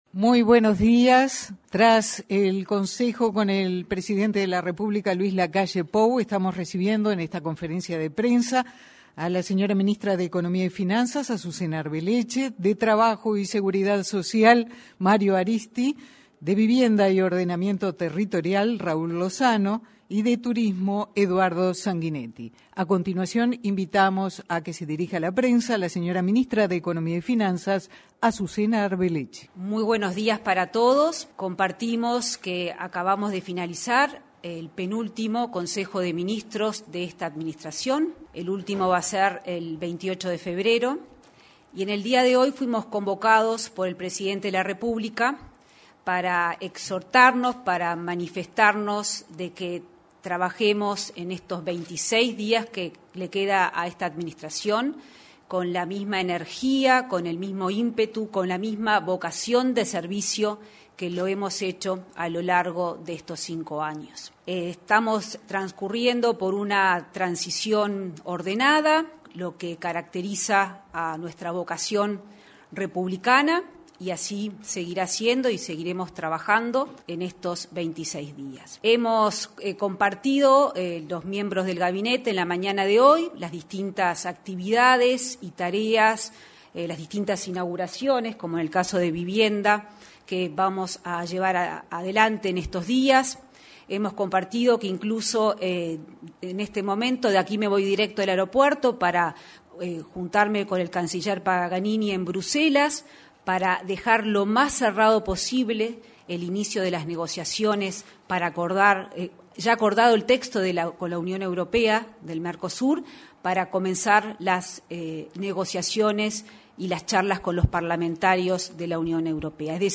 Conferencia de Prensa - Consejo de Ministros
Conferencia de Prensa - Consejo de Ministros 03/02/2025 Compartir Facebook X Copiar enlace WhatsApp LinkedIn Este 3 de febrero, se realizó, en la sala de la prensa de la Torre Ejecutiva, una conferencia en la que participaron los ministros de Economía y Finanzas, Azucena Arbeleche; Vivienda y Ordenamiento Territorial, Raúl Lozano; Trabajo y Seguridad Social, Mario Arizti, y Turismo, Eduardo Sanguinetti. Luego, los jerarcas dialogaron con los medios informativos presentes.